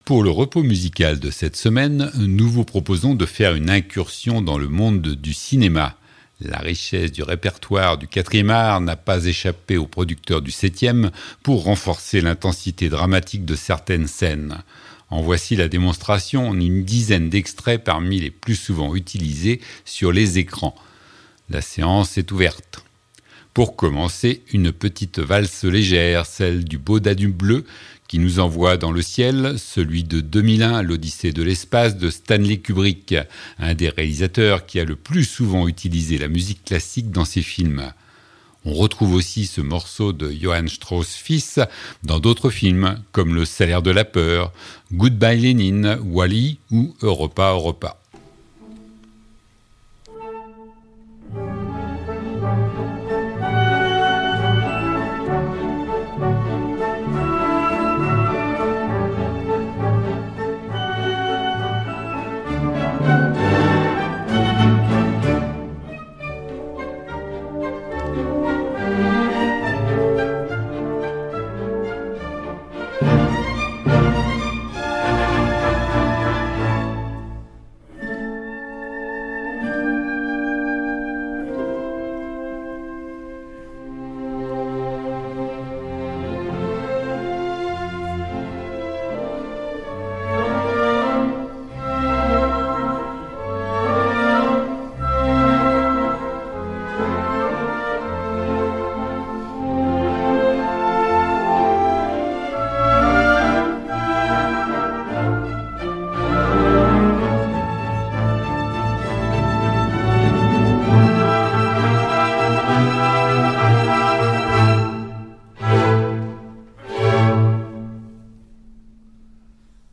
Musique de film